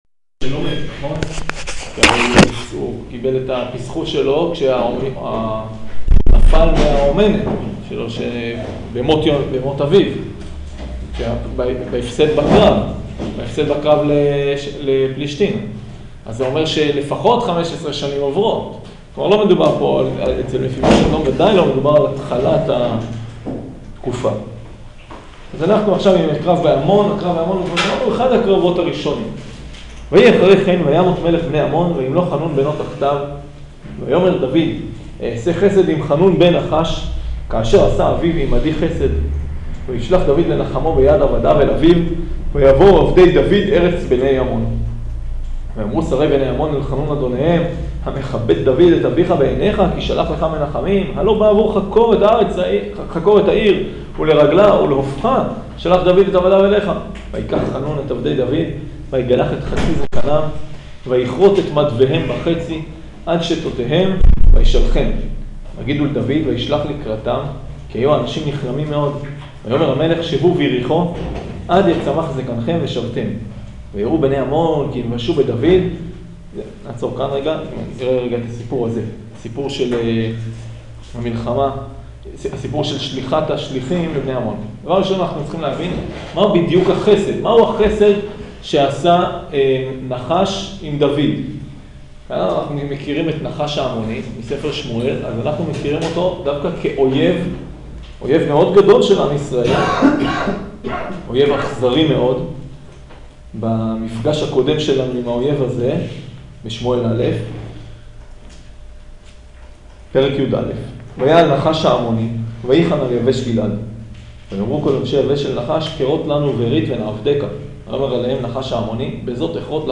שיעור שמואל ב' פרק י'